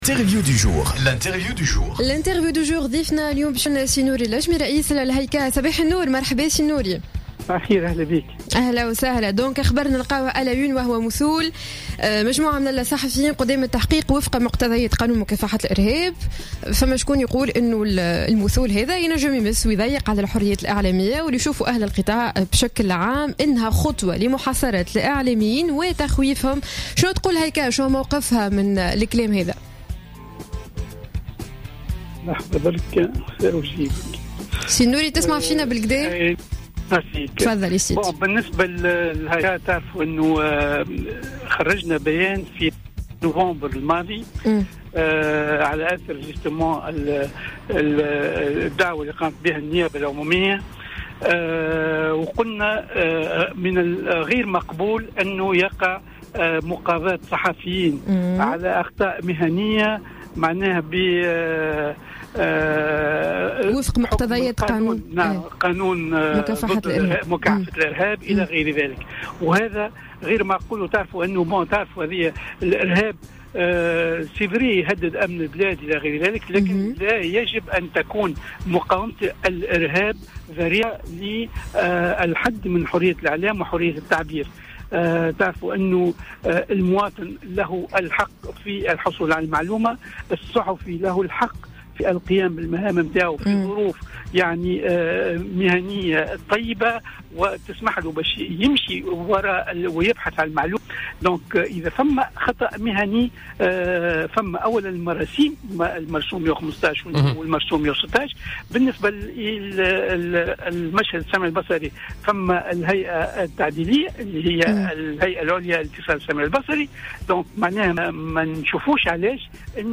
أكد رئيس الهيئة العليا للإتصال السمعي البصري النوري اللجمي في مداخلة له على الجوهرة "اف ام" صباح اليوم الإثنين 18 جانفى 2016 أن مثول عدد من الصحفيين أمام القضاء بمقتضى قانون مكافحة الإرهاب أمر غير مقبول ويعد خرقا لحرية الإعلام وحرية التعبير.